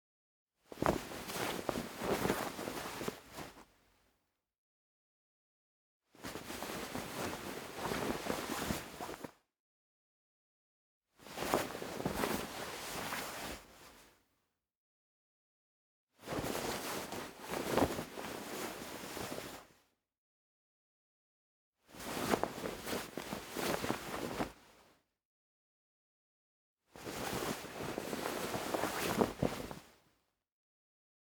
household
Cloth Movement 6